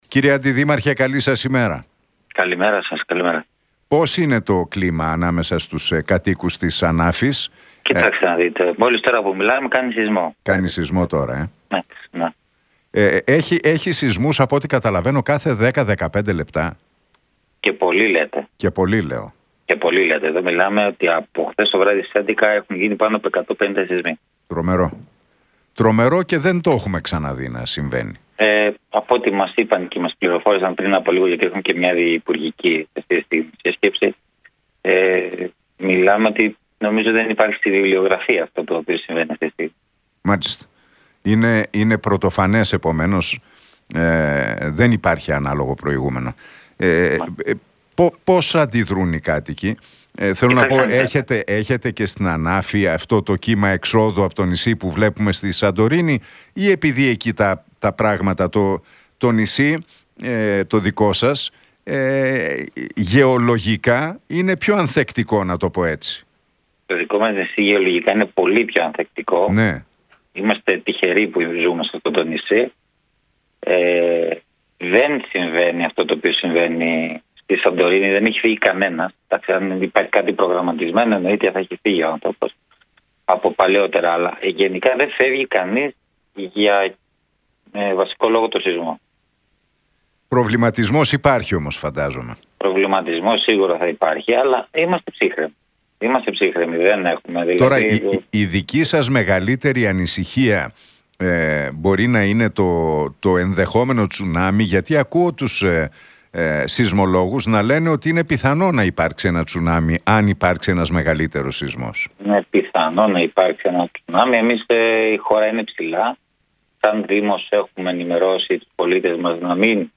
Για την κατάσταση που επικρατεί στην Ανάφη με τους αλλεπάλληλους σεισμούς μίλησε ο αντιδήμαρχος του νησιού, Σοφούλης Κολυδάς στον Νίκο Χατζηνικολάου από την συχνότητα του Realfm 97,8.